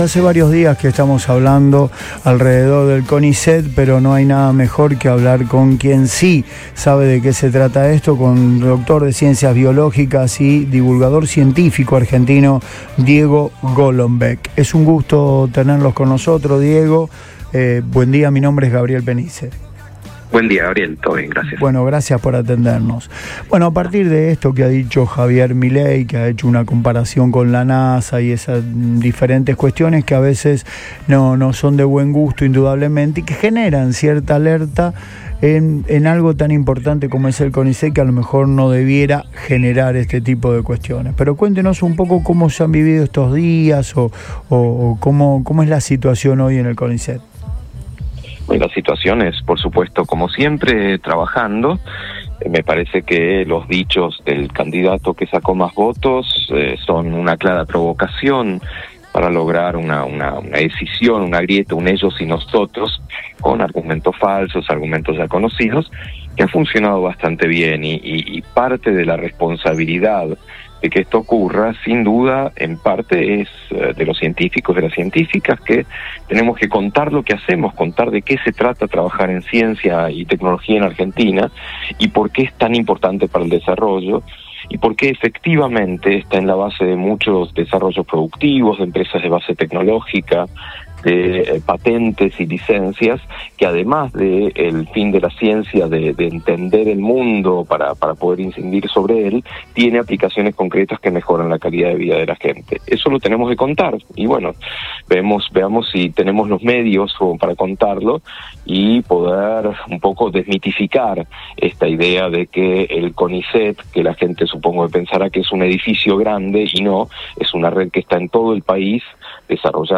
EN RADIO BOING
Diego Golombek, divulgador científico